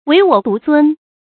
惟我独尊 wéi wǒ dú zūn
惟我独尊发音
成语注音ㄨㄟˊ ㄨㄛˇ ㄉㄨˊ ㄗㄨㄣ